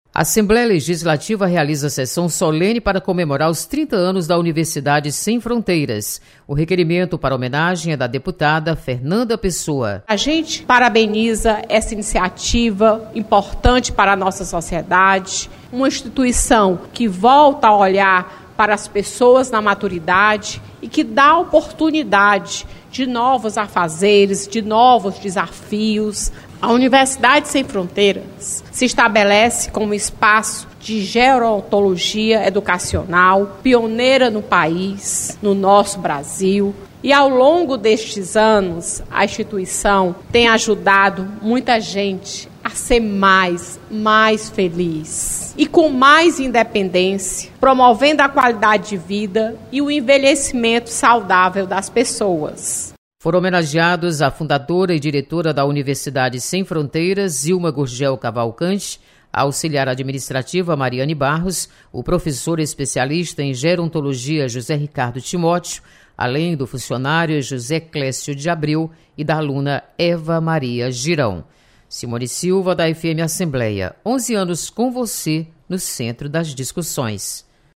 Sessão Solene
Universidade Sem Fronteiras é homenageada na Assembleia Legislativa. Repórter